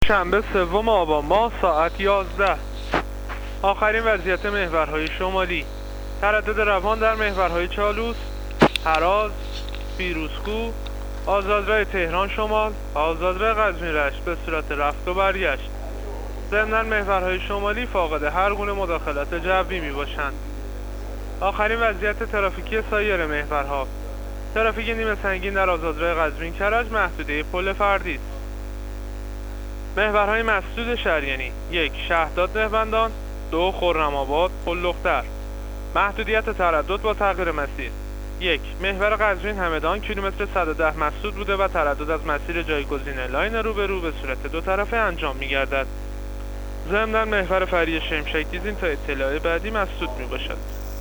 گزارش رادیو اینترنتی از وضعیت ترافیکی جاده‌ها تا ساعت ۱۱ ‌شنبه ۳ آبان